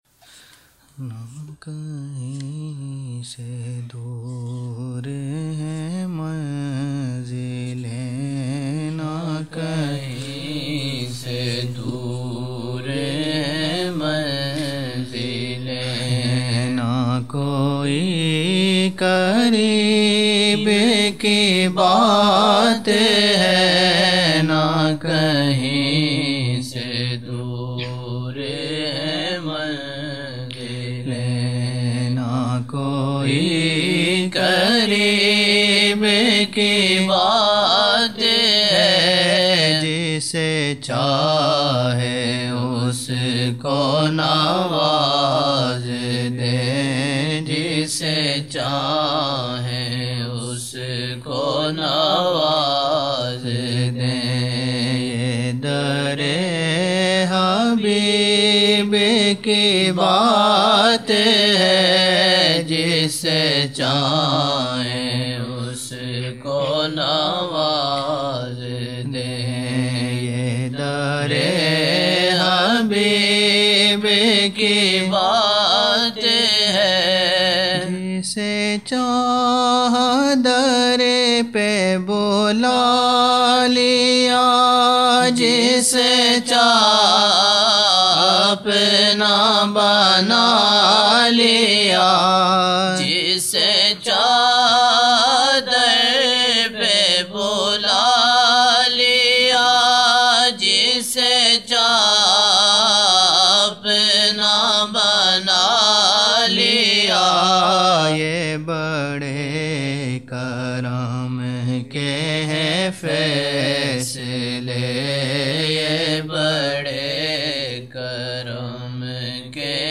26 November 1999 - Maghrib mehfil (18 Shaban 1420)
Naat shareef